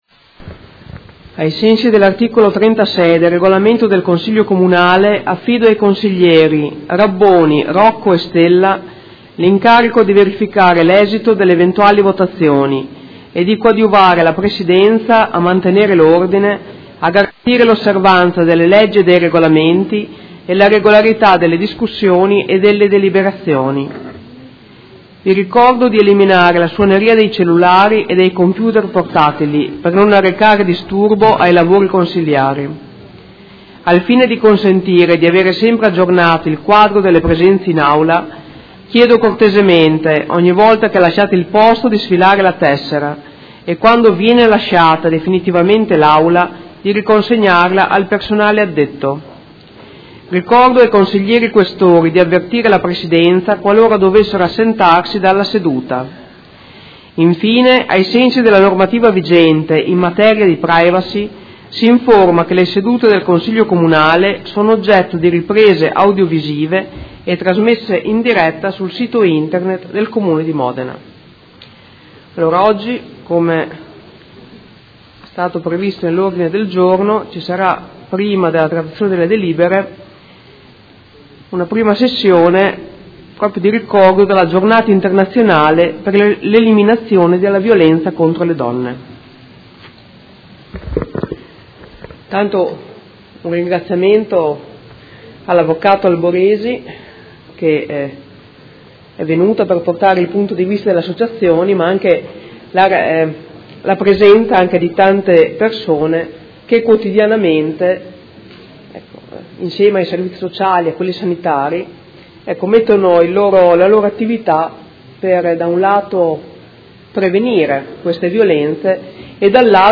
Presidentessa — Sito Audio Consiglio Comunale
Seduta del 24/11/2016 Apre i lavori del Consiglio Comunale. Introduzione al dibattito sulla giornata internazionale per l'eliminazione della violenza contro le donne.